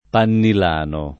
vai all'elenco alfabetico delle voci ingrandisci il carattere 100% rimpicciolisci il carattere stampa invia tramite posta elettronica codividi su Facebook pannolano [ pannol # no ] s. m.; pl. pannilani — meno com. panno lano [id.] e pannilano [ pannil # no ]